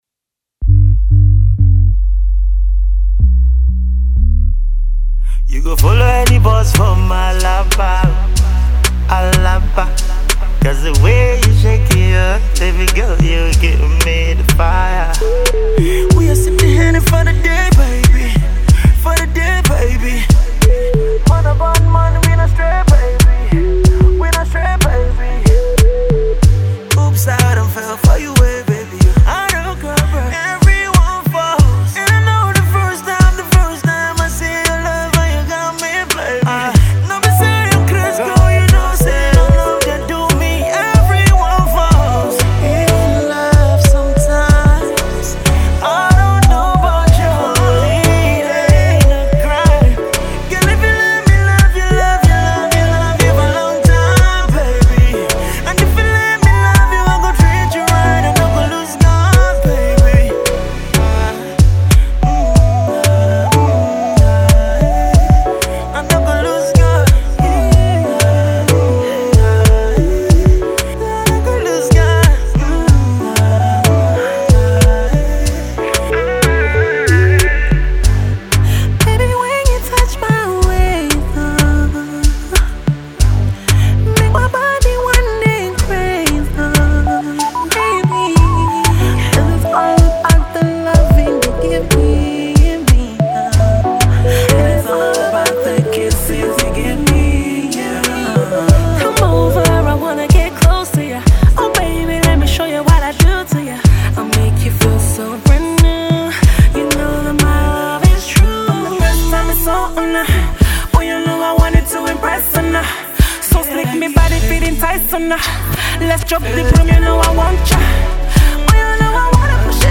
U.K. based pop sensation Girl group